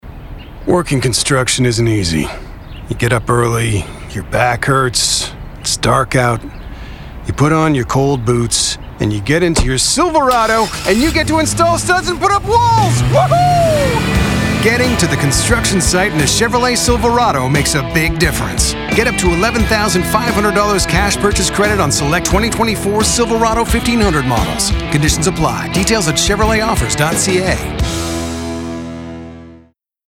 Commercial (Silverado) - EN